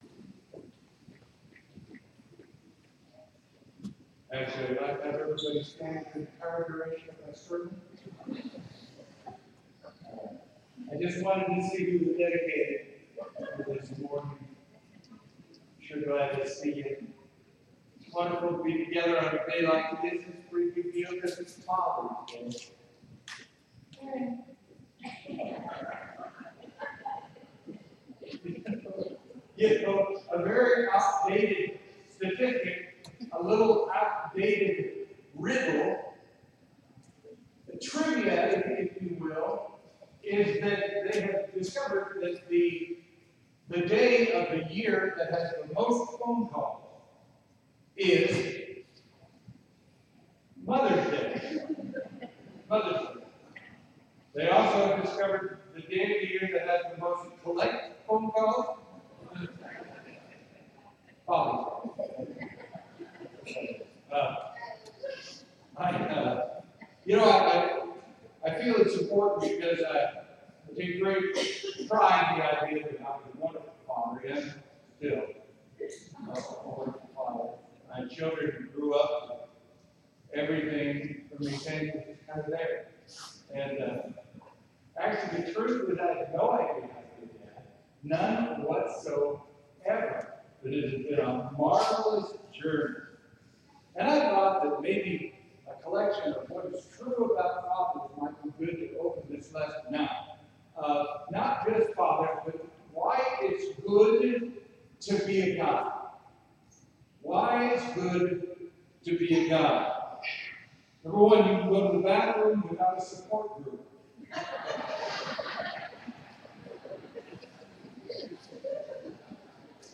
Sermon: “The Converted Disciple”